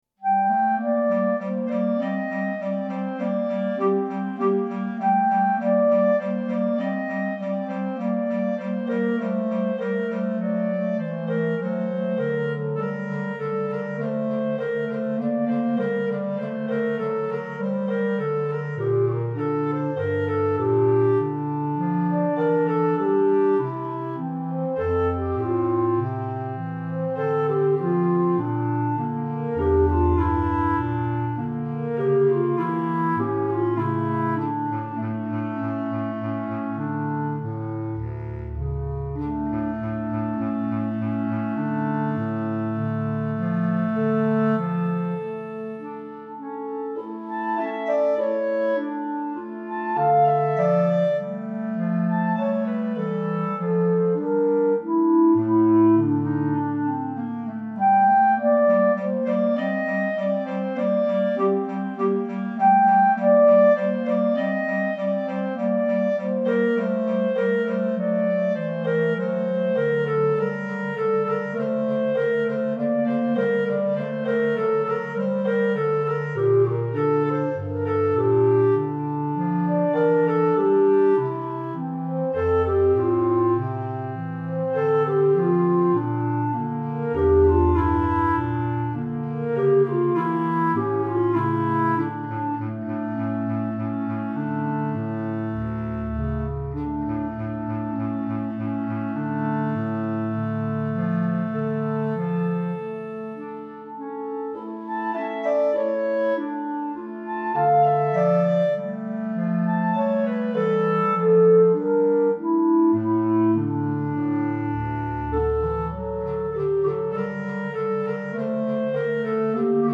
Right click to download Minuet minus Clarinet 2